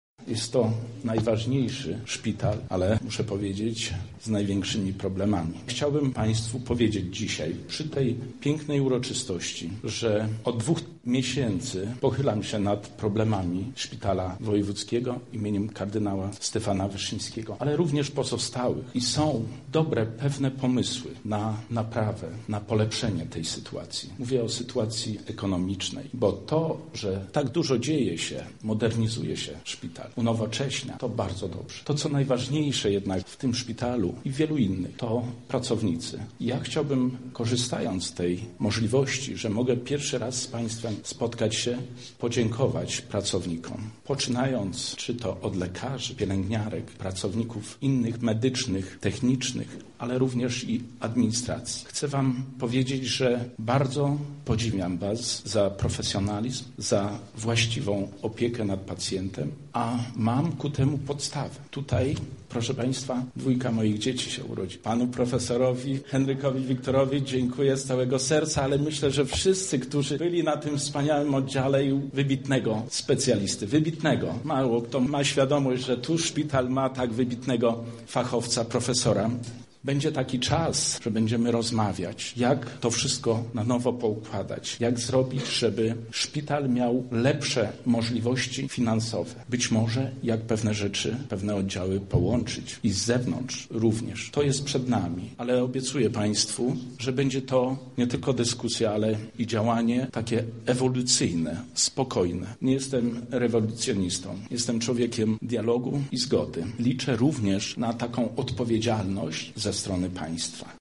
Podczas prezentacji nowo oddanego oddziału nie zabrakło także obecności lubelskich polityków.
Jak mówi mówi wicemarszałek Zbigniew Wojciechowski: Szpital przy alei Kraśnickiej jest najważniejszy w województwie.